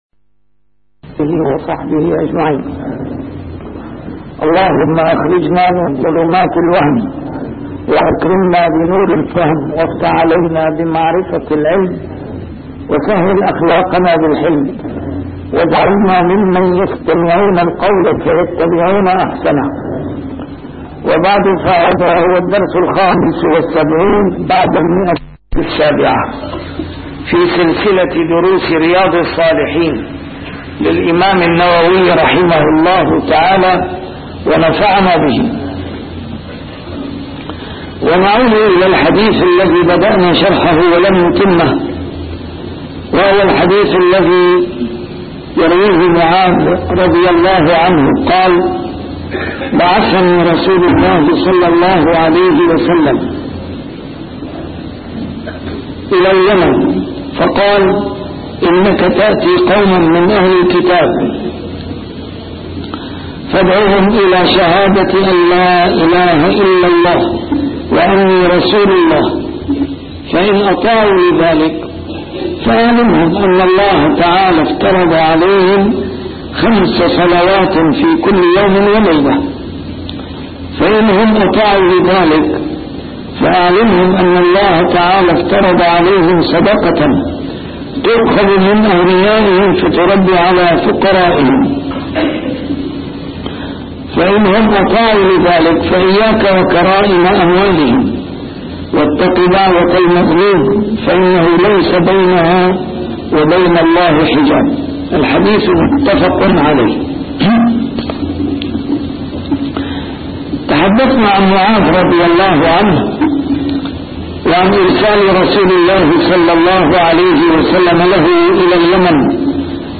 A MARTYR SCHOLAR: IMAM MUHAMMAD SAEED RAMADAN AL-BOUTI - الدروس العلمية - شرح كتاب رياض الصالحين - 775- شرح رياض الصالحين: المحافظة على الصلوات المكتوبات